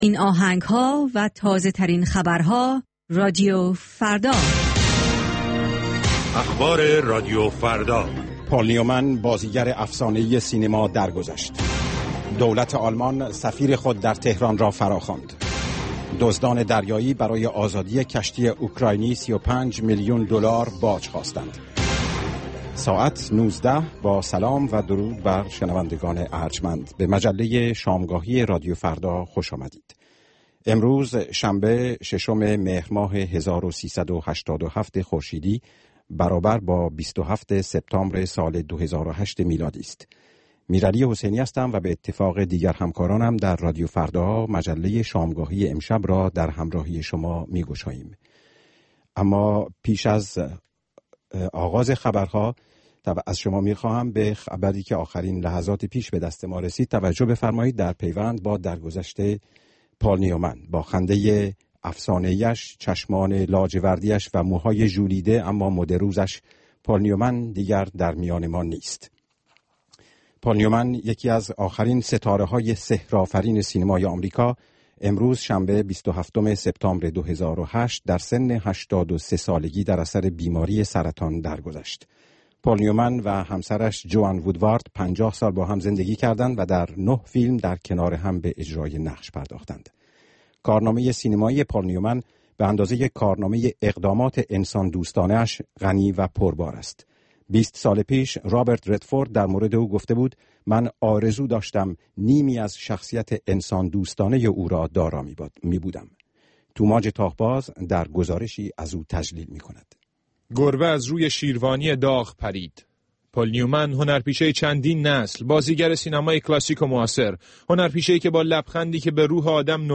مجموعه ای متنوع از آنچه در طول روز در سراسر جهان اتفاق افناده است. در نیم ساعات مجله شامگاهی رادیو فردا، آخرین خبرها و تازه ترین گزارش های تهیه کنندگان این رادیو فردا پخش خواهند شد.